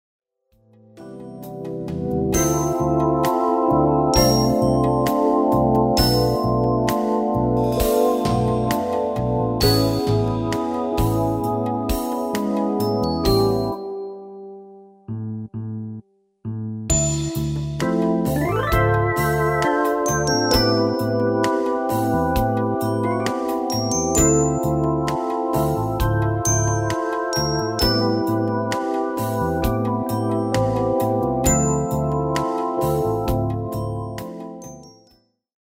Demo/Koop midifile
Genre: Evergreens & oldies
- Géén tekst
- Géén vocal harmony tracks
Demo's zijn eigen opnames van onze digitale arrangementen.